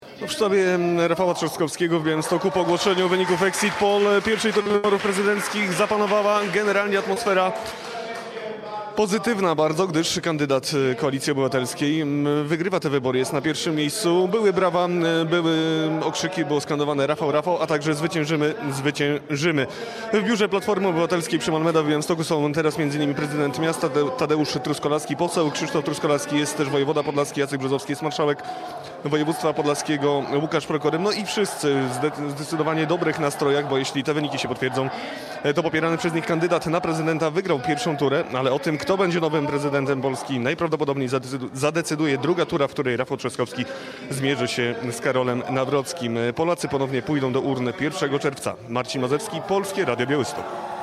Pierwsze reakcje po ogłoszeniu sondażowych wyników wyborów prezydenckich w białostockim sztabie Rafała Trzaskowskiego